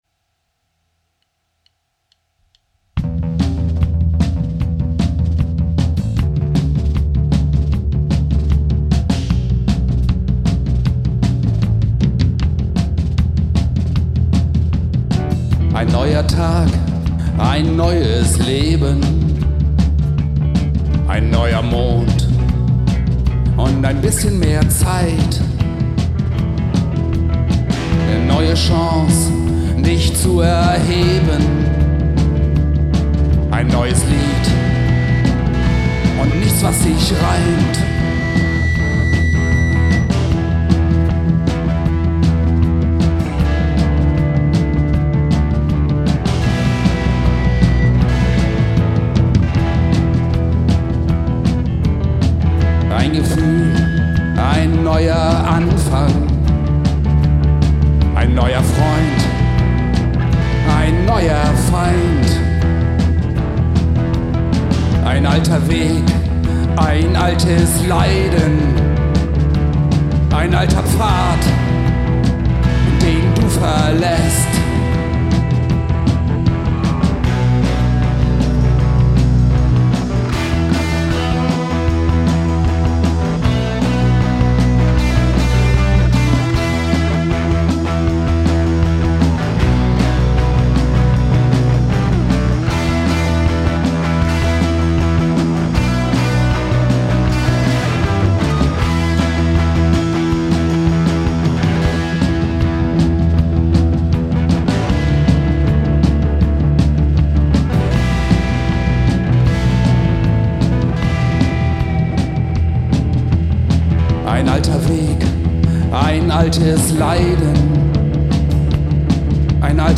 Tr�mmerrock & Powerpop